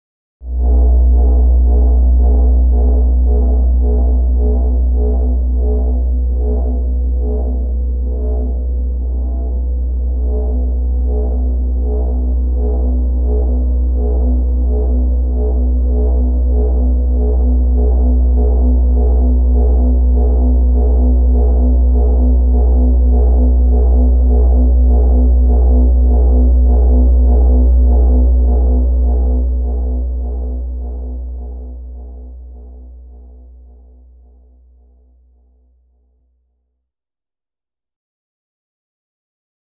Electric Generator | Sneak On The Lot
Electric Generator; Large Generator; Low Frequency Tone With Higher Warble Tone Above, Close Perspective.